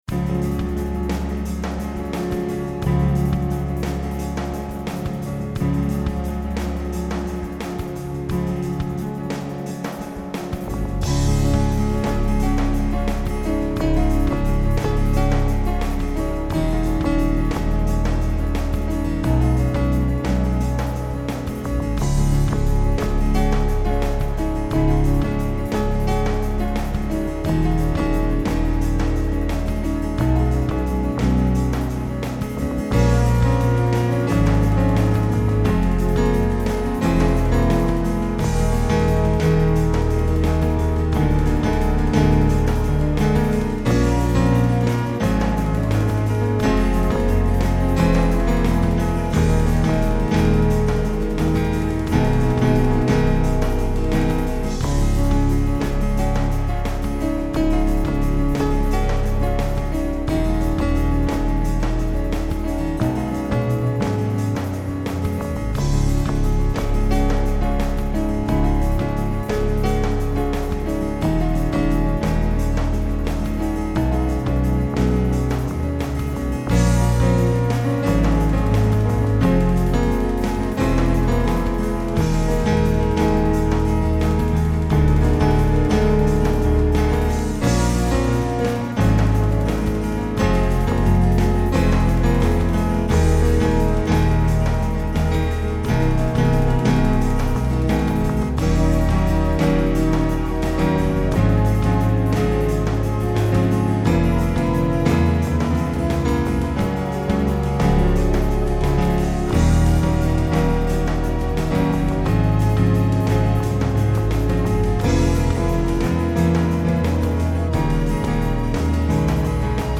I wanted to do a song with french horns like Comfortably Numb